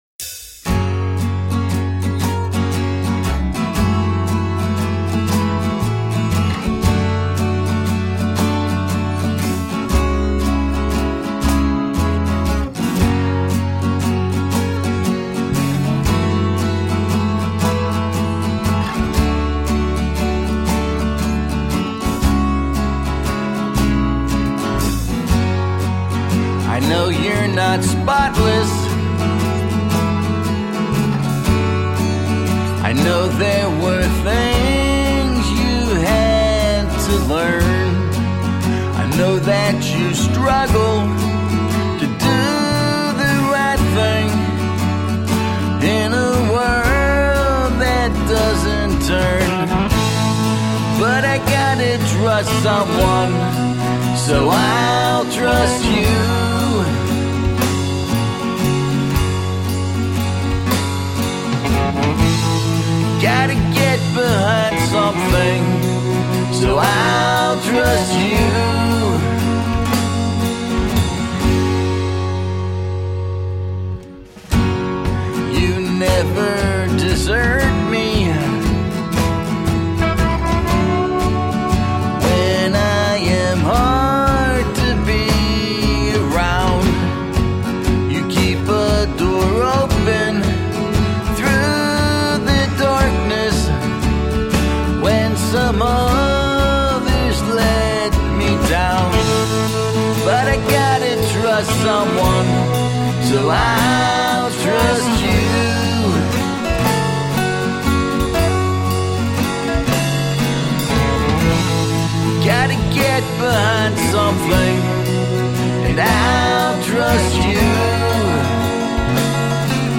Roots rock meets contemporary folk.
Tagged as: Alt Rock, Folk